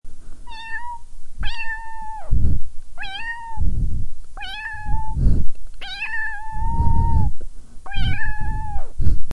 Download Cats sound effect for free.
Cats